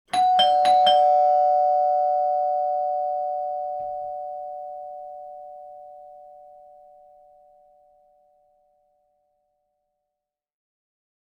جلوه های صوتی
دانلود صدای زنگ در 2 از ساعد نیوز با لینک مستقیم و کیفیت بالا
برچسب: دانلود آهنگ های افکت صوتی اشیاء دانلود آلبوم صدای زنگ در خانه از افکت صوتی اشیاء